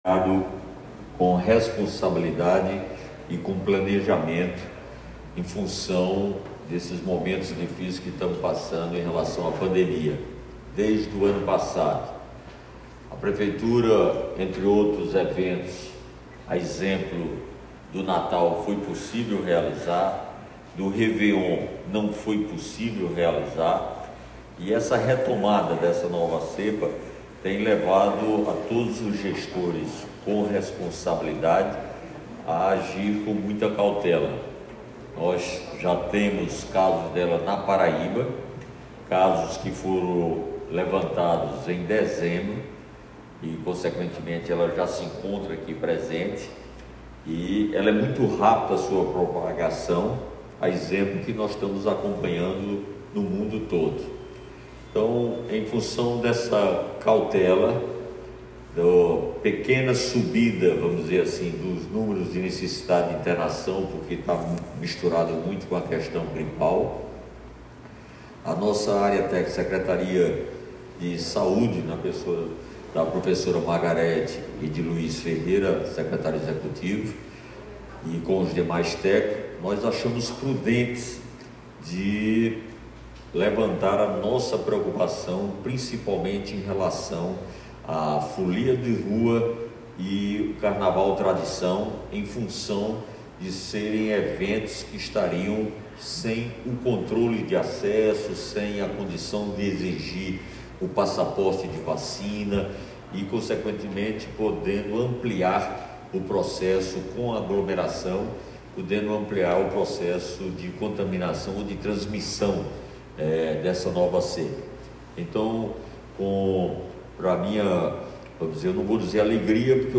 A ideia é reduzir o público de forma que se possa haver controle, fiscalização e testagem de vacinados e dos protocolos sanitários. O anúncio foi feito durante coletiva de imprensa no Centro Administrativo Municipal (CAM), em Água Fria.